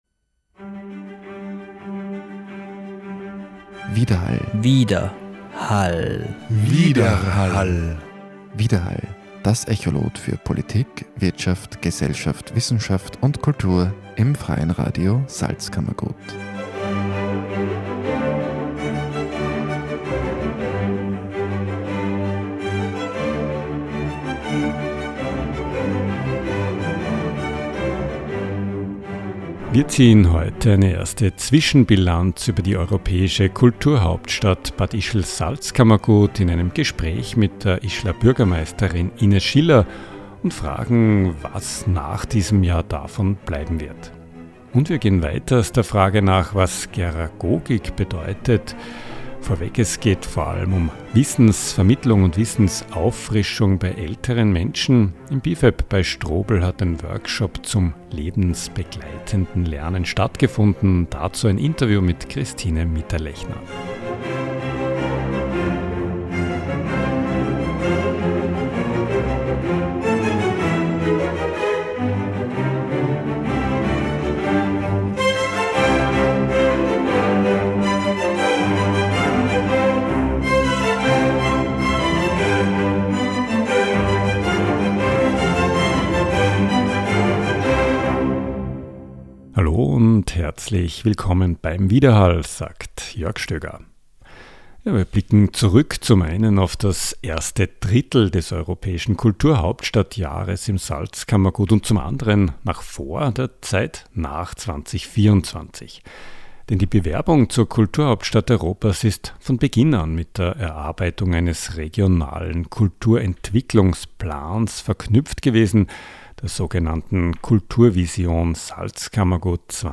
Das Freie Radio Salzkammergut fragte bei der Bad Ischler Bürgermeisterin Ines Schiller in der ersten Maiwoche 2024 nach, wie es mit der Umsetzung der Kulturvision Salzkammergut 2030, gemeinsamer Leitlinien und Rahmenbedingungen für das kulturelle Schaffen im Salzkammergut bis 2030 weitergeht?